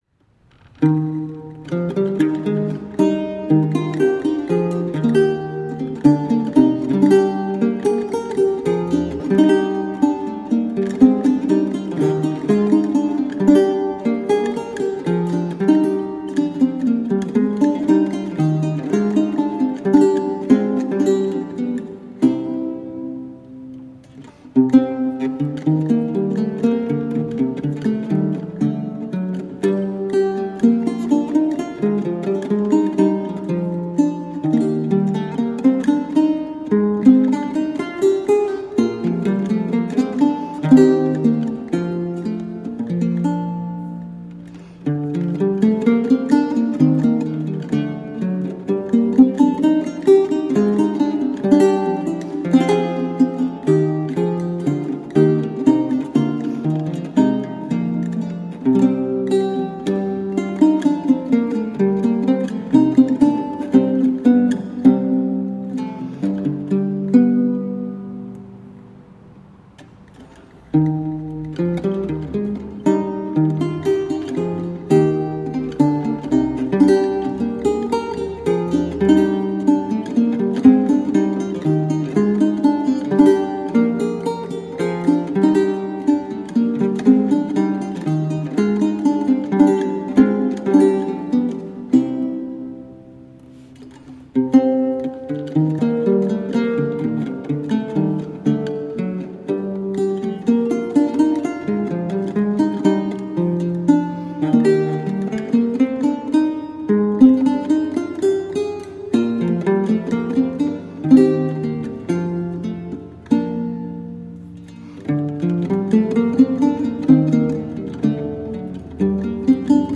Vihuela, renaissance and baroque lute.
Classical, Renaissance, Instrumental, Lute